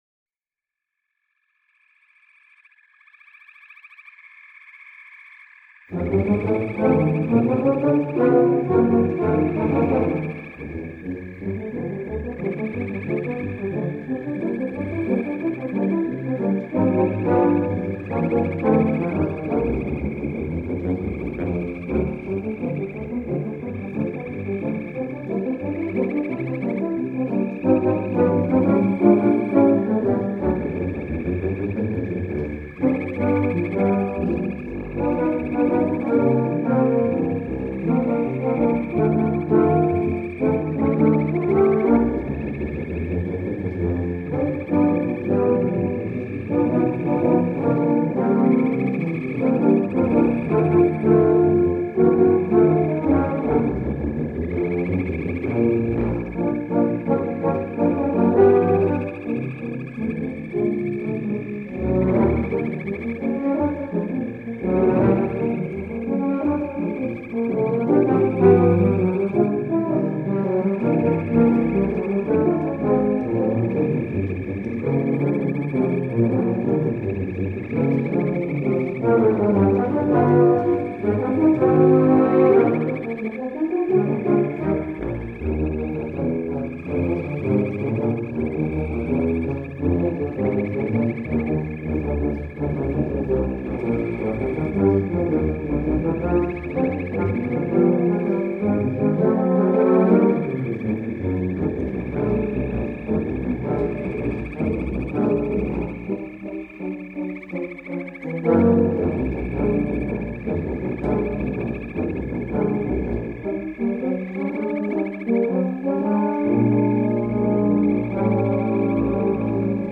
For Tuba Quartet (EETT)